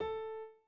01_院长房间_钢琴_02.wav